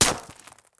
auto_hit_stone1.wav